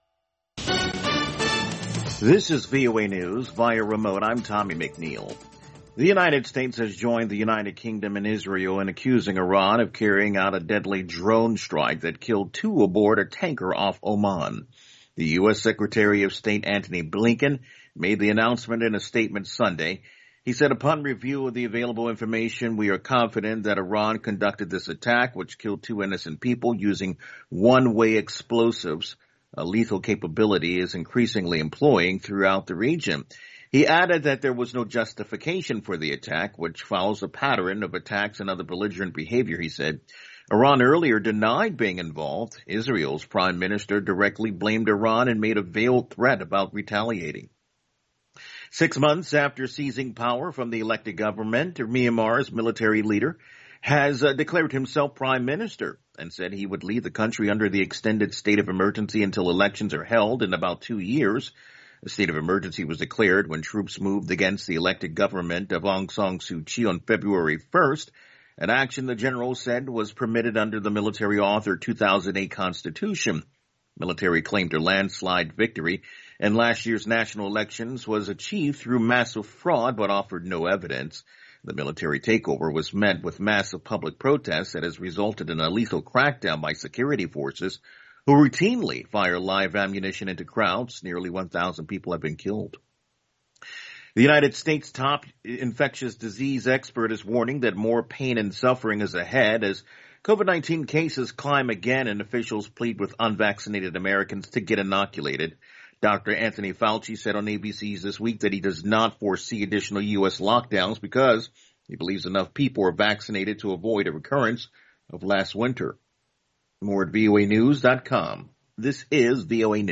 VOA Newscasts (2 Minute)